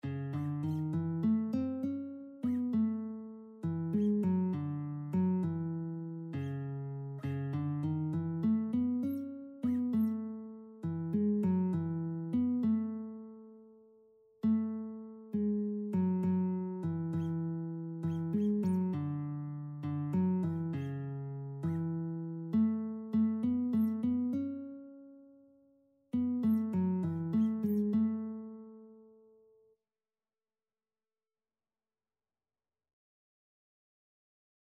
Christian
6/8 (View more 6/8 Music)
Classical (View more Classical Lead Sheets Music)